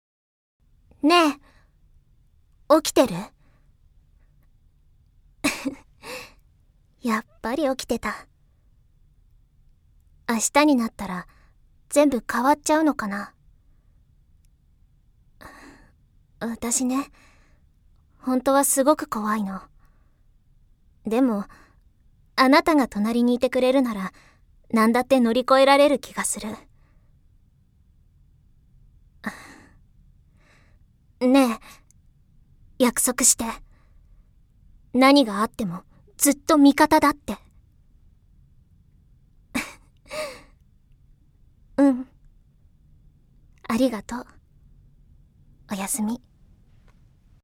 ◆大人しい女の子◆